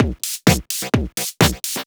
Electrohouse Loop 128 BPM (23).wav